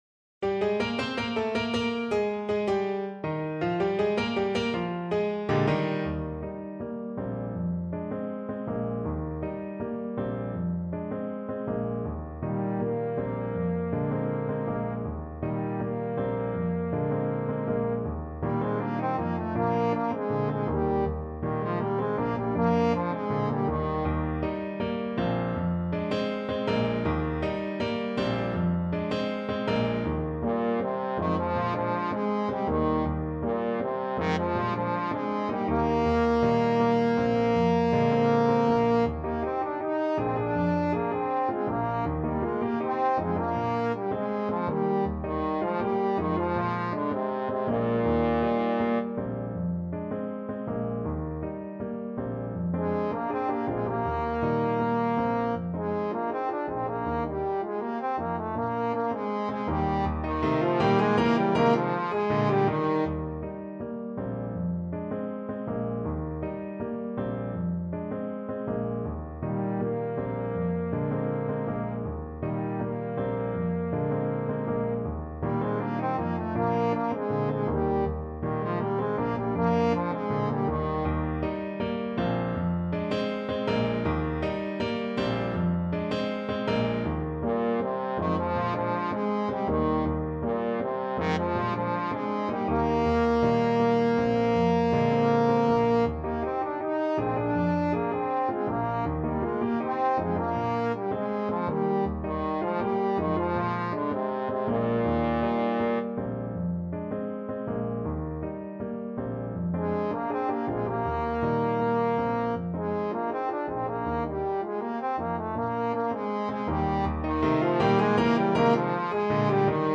Allegretto =80
Cuban